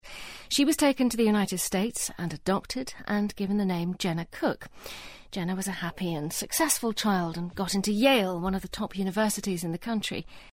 【英音模仿秀】孤女寻亲记 听力文件下载—在线英语听力室